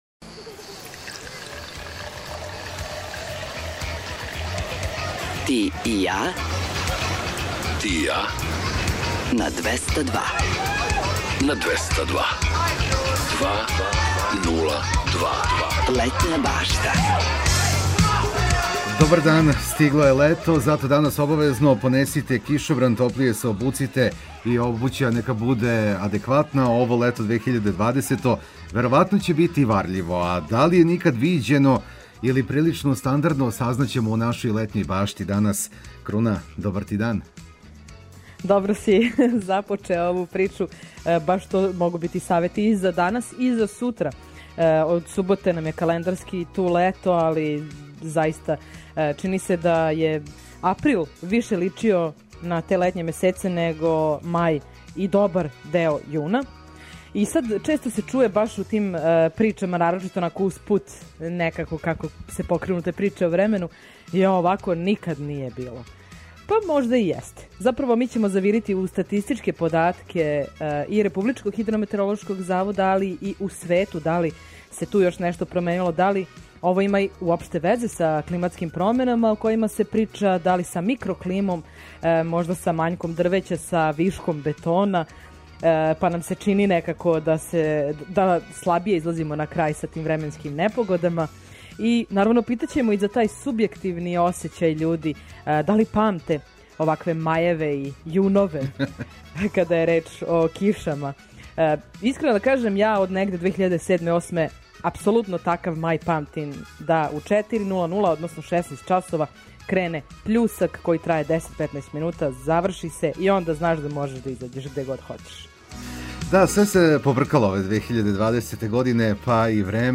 Цео други сат резервисан је за музичке приче: датумске песме подсећају нас на рођендане музичара као и годишњице објављивања албума, синглова и других значајних догађаја из историје попа и рокенрола. Ту је и пола сата резервисано само са музику из Србије и региона, а упућујемо вас и на нумере које су актуелне.